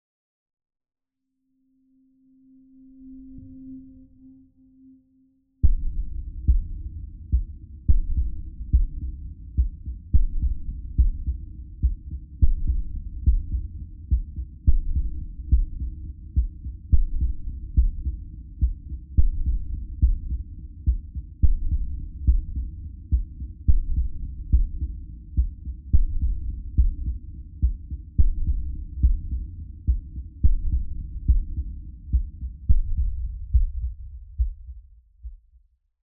STest1_Right200Hz.flac